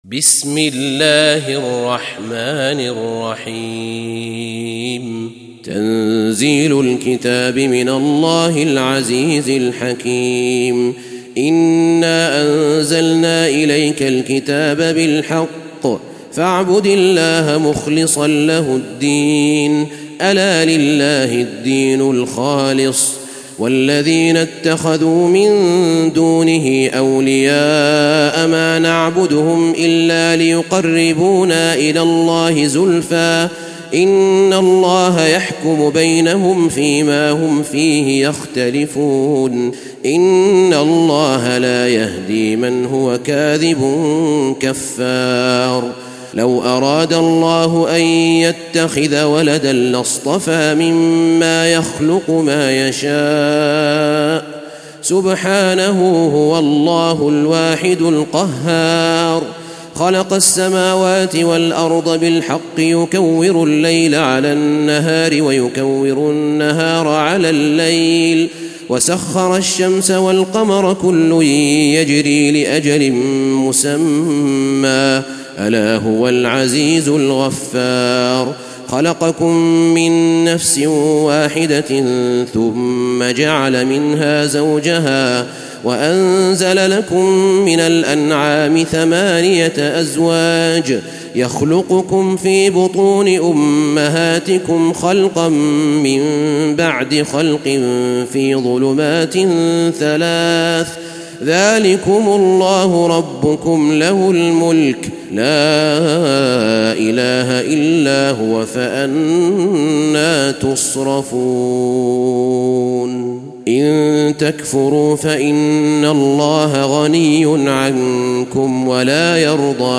تلاوة سورة الزمر
تاريخ النشر ١ محرم ١٤٣٤ هـ المكان: المسجد النبوي الشيخ: فضيلة الشيخ أحمد بن طالب بن حميد فضيلة الشيخ أحمد بن طالب بن حميد سورة الزمر